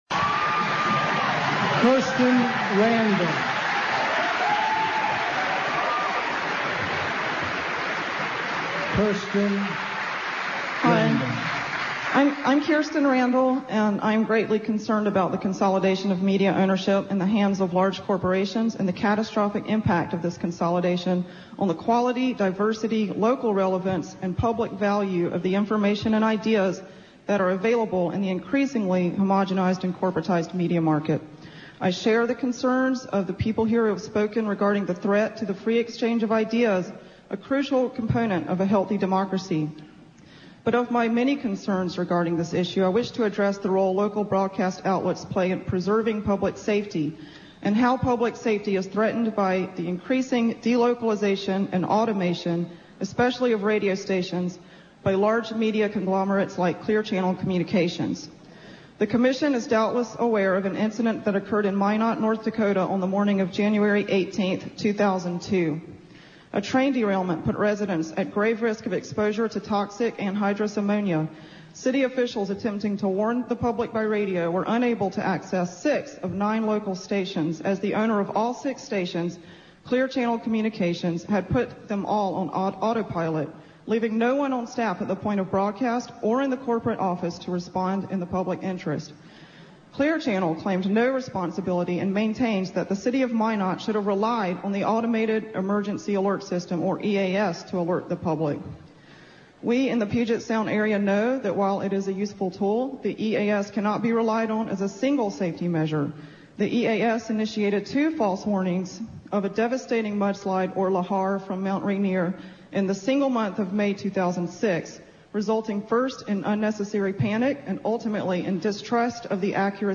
FCC holds public hearings on deregulation : Indybay